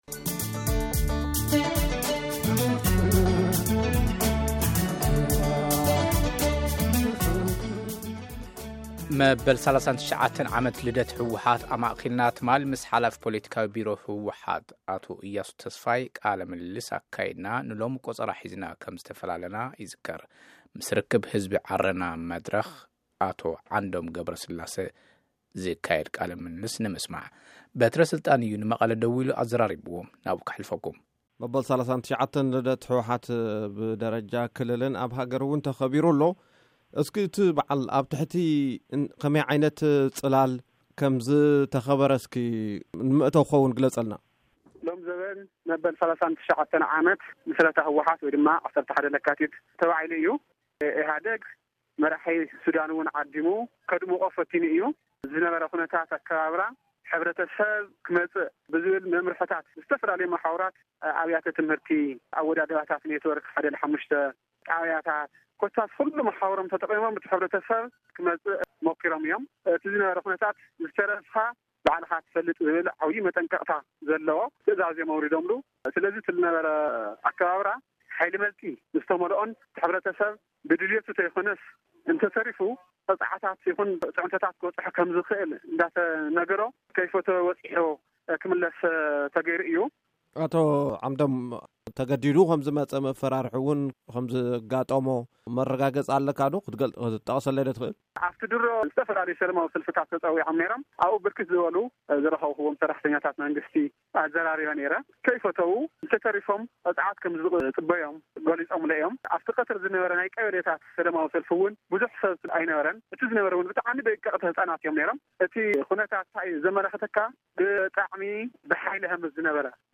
ሙሉእ ቃለ-ምልልስ ምስ ርክብ ህዝቢ ዓረና ትግራይ